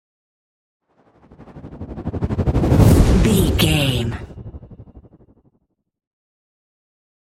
Whoosh to hit trailer long
Sound Effects
Fast paced
In-crescendo
Atonal
dark
intense
tension